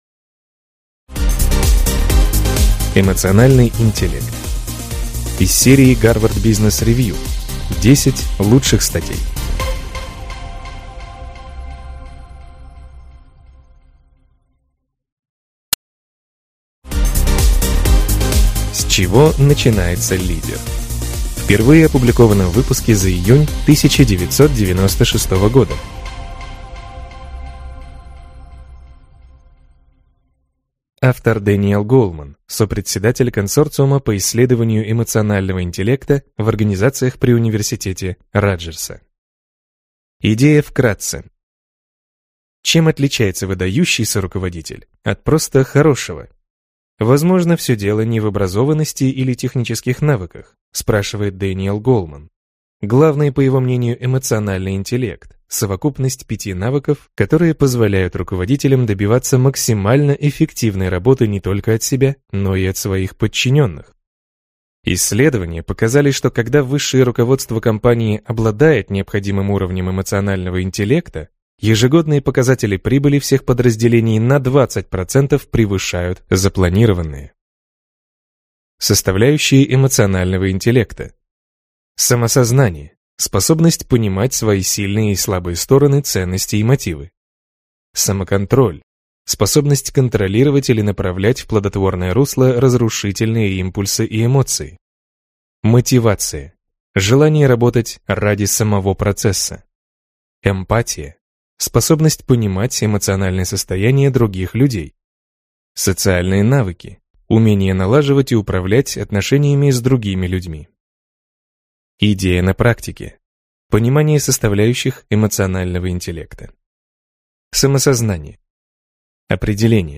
Аудиокнига Эмоциональный интеллект | Библиотека аудиокниг